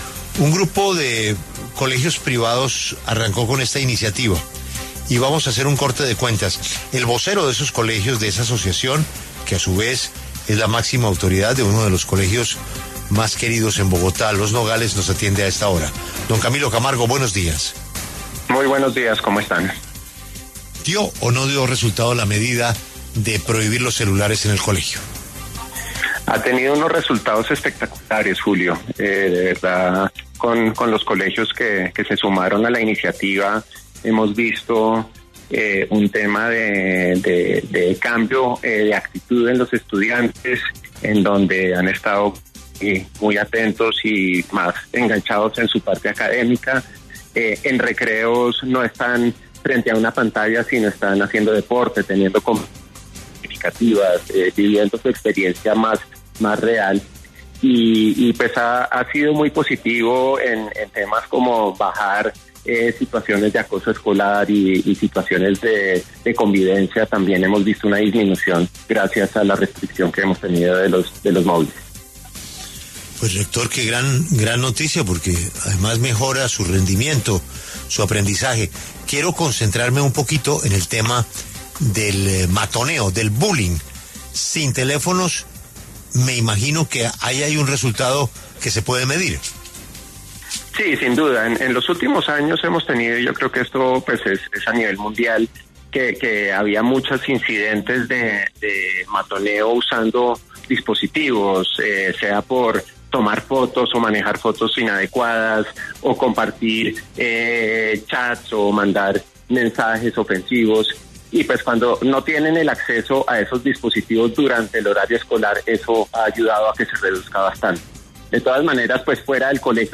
Desde hace 8 meses los colegios que conforman la Unión de Colegios Internacionales de Bogotá (Uncoli) acordaron restringir el uso de estos dispositivos durante el horario escolar. En La W contaron los resultados.